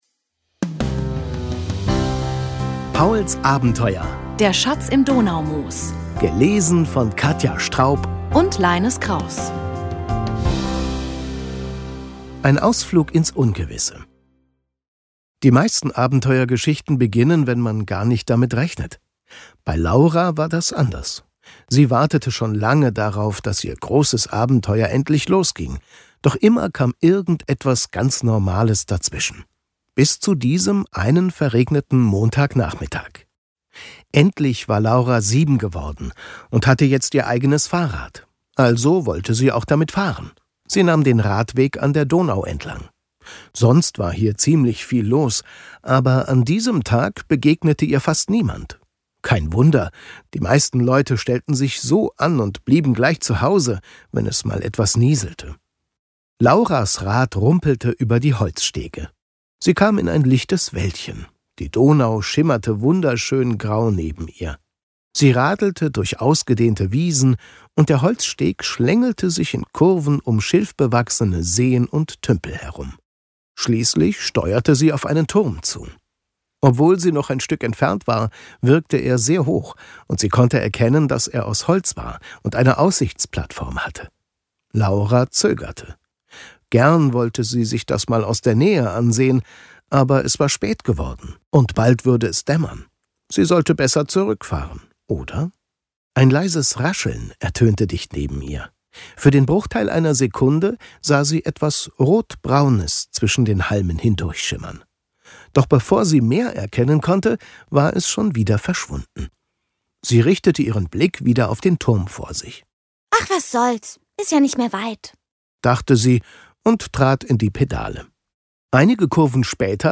Hörbücher